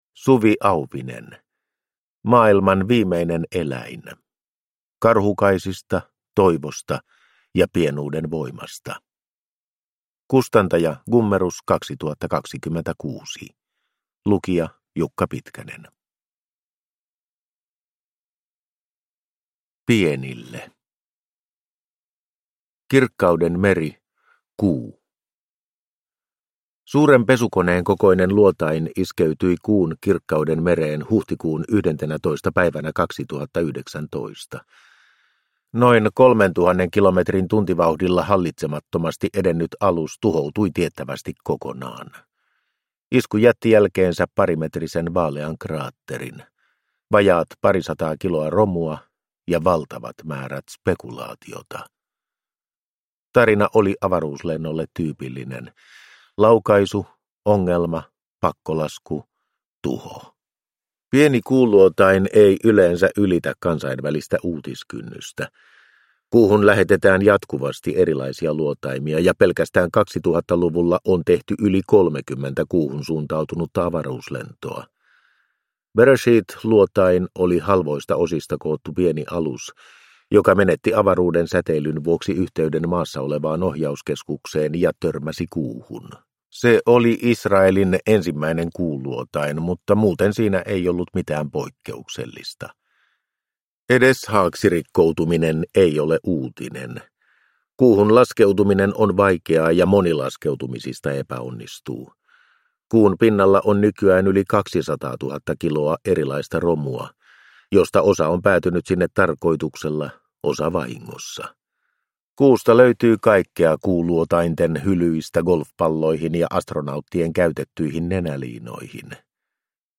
Maailman viimeinen eläin – Ljudbok